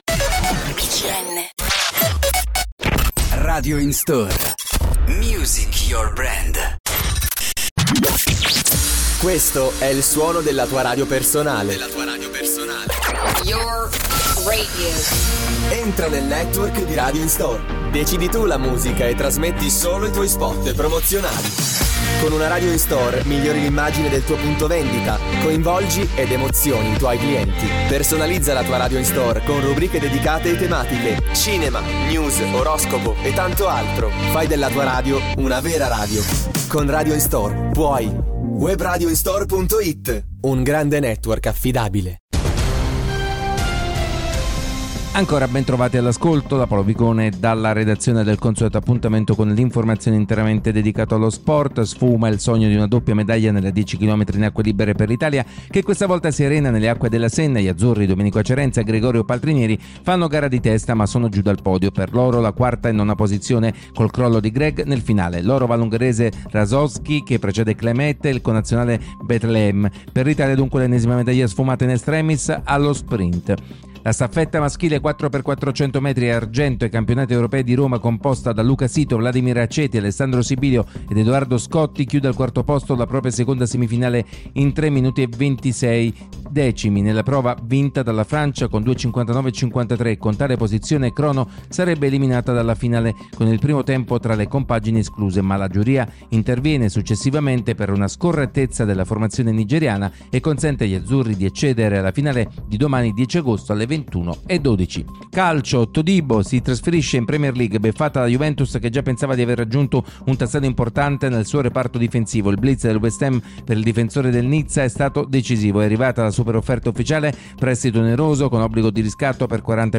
Publicitat de Radio In Store (la ràdio personal), informatiu esportiu dedicada a la jornada dels Jocs Olímpics de París 2024 i a la lliga italiana de futbol. Indicatiu de Radio BCN La radio italiana i tema musical
Esportiu